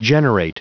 Prononciation du mot generate en anglais (fichier audio)
Prononciation du mot : generate